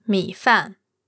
mi3fan4.mp3